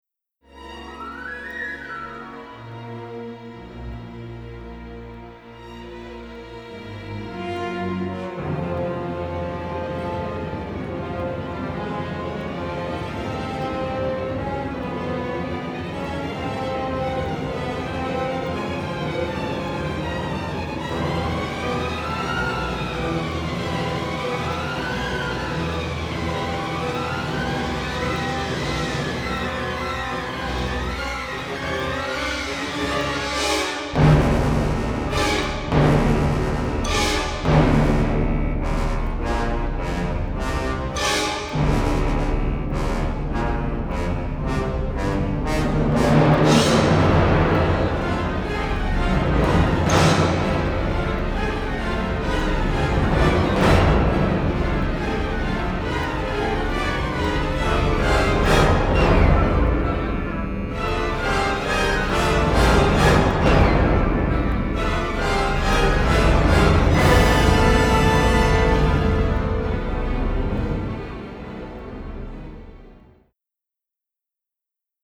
records and mixes at AIR Studios in London